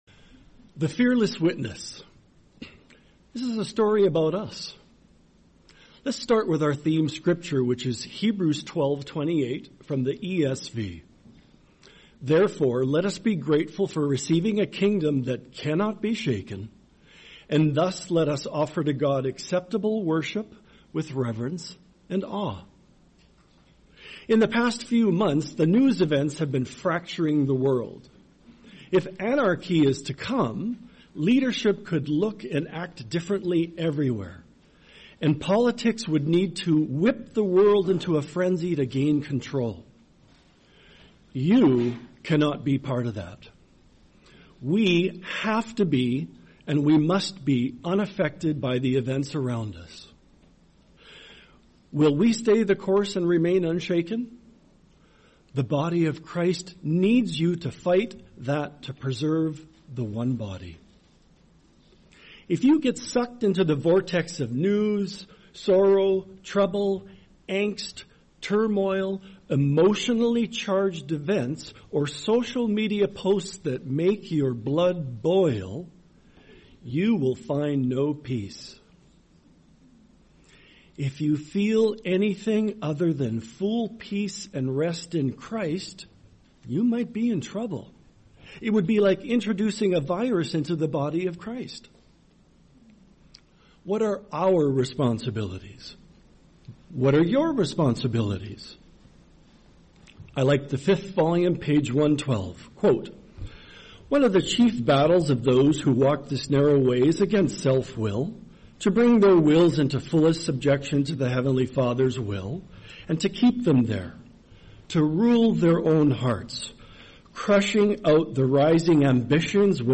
Series: 2026 Florida Convention